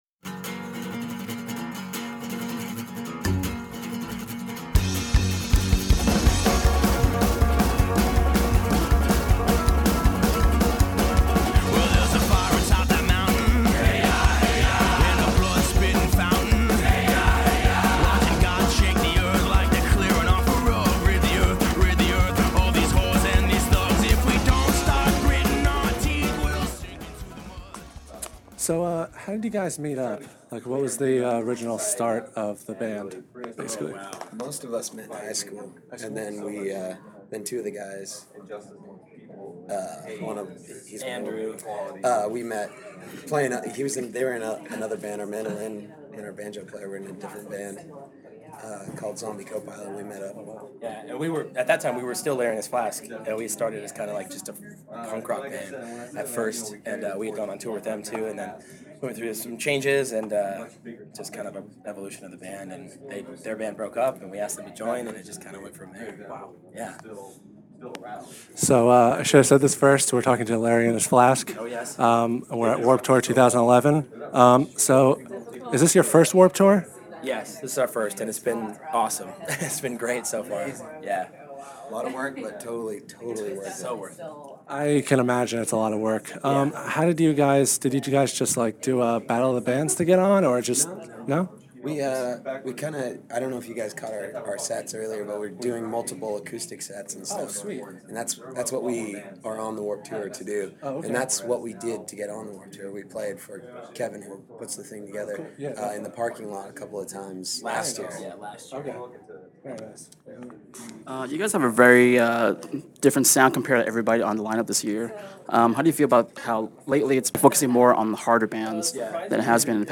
Check out this interview we did with Larry and His Flask.